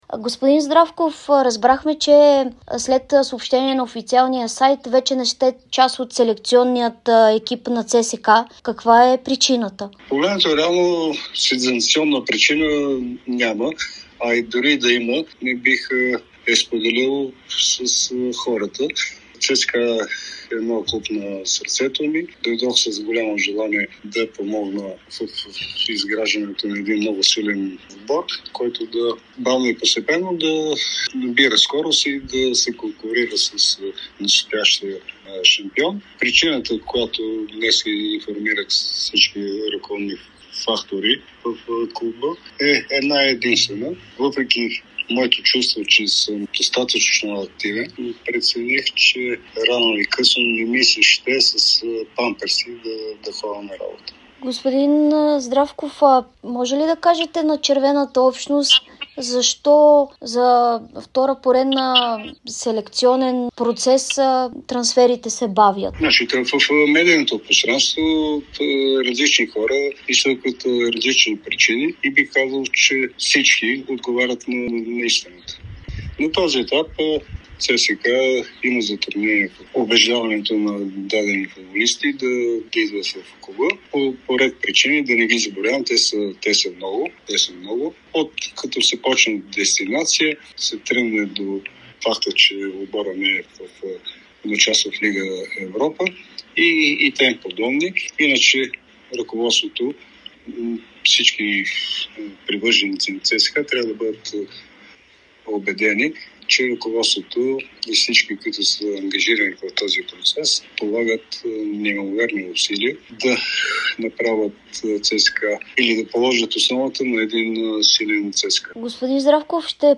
Доскорошният член на скаутския отдел на ЦСКА - Радослав Здравков, даде интервю за Дарик радио и Dsport.